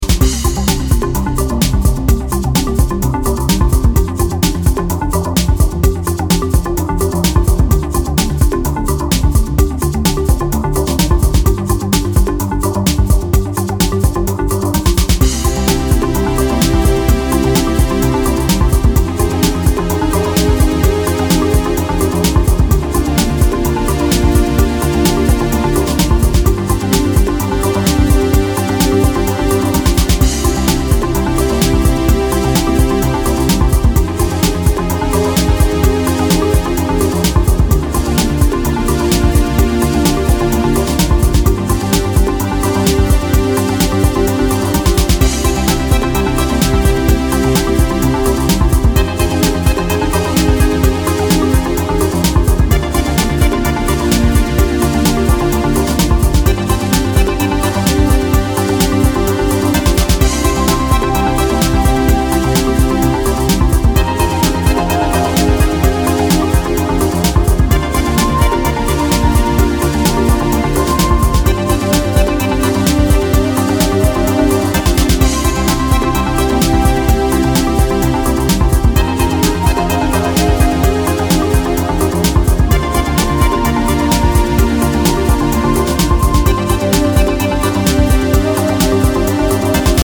as part of a five-track EP of deep house and electro
and adds punchier drums more suited to today’s clubs.